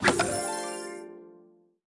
Media:ArcherQueen_evo2_dep.wav 部署音效 dep 在角色详情页面点击初级、经典、高手和顶尖形态选项卡触发的音效